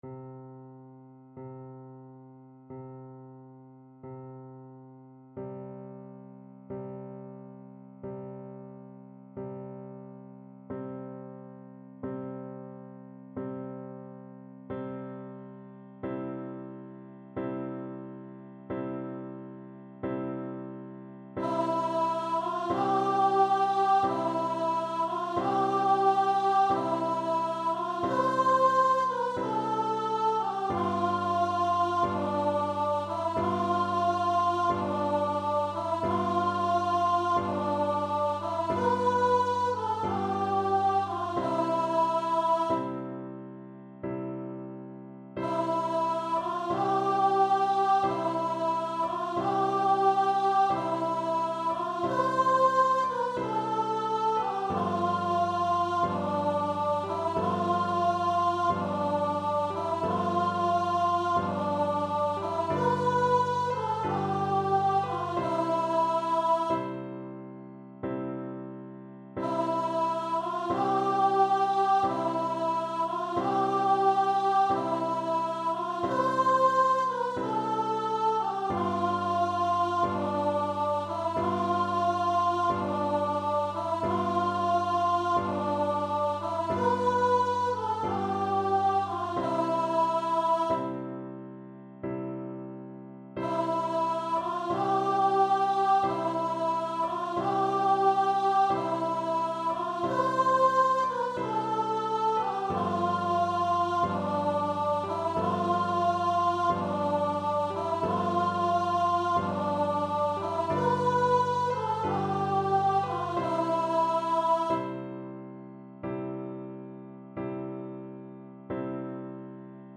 Free Sheet music for Voice
Voice
Traditional Music of unknown author.
C major (Sounding Pitch) (View more C major Music for Voice )
Slowly = c.45
D5-C6
2/4 (View more 2/4 Music)
Classical (View more Classical Voice Music)